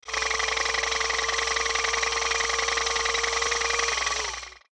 audio: Converted sound effects
Golf_Moving_Barrier.ogg